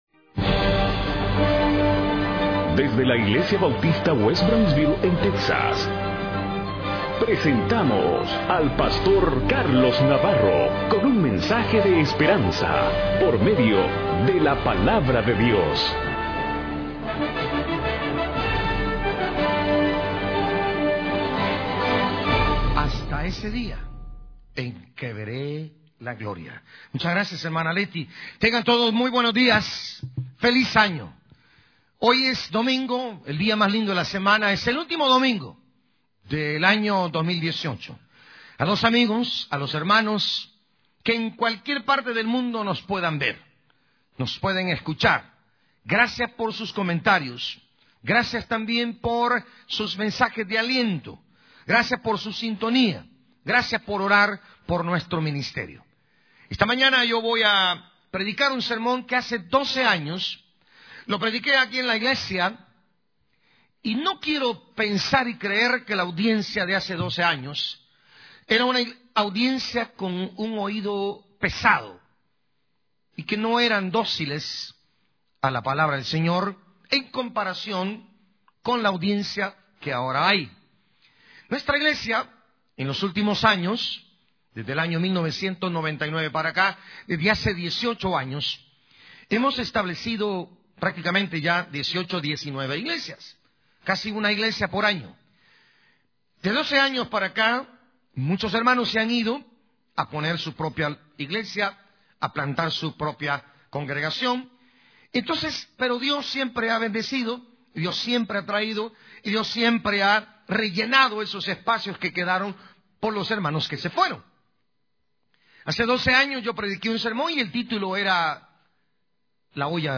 Predicador